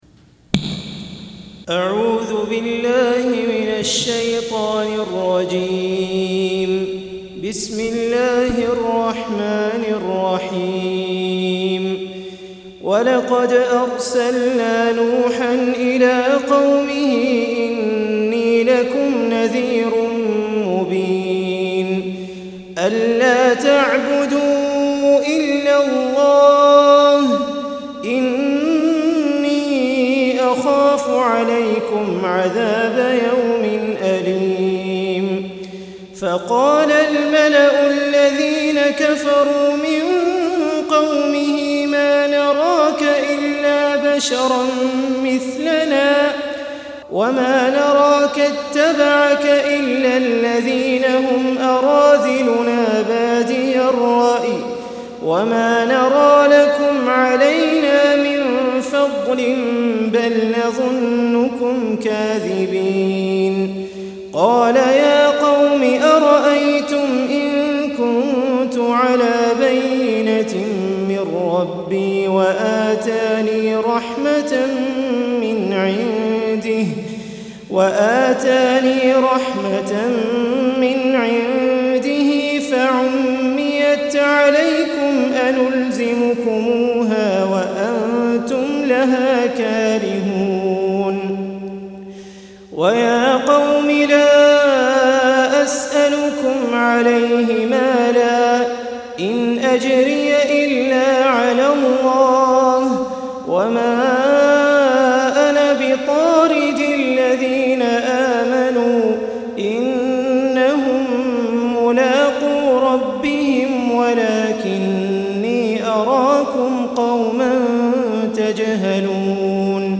213- عمدة التفسير عن الحافظ ابن كثير رحمه الله للعلامة أحمد شاكر رحمه الله – قراءة وتعليق –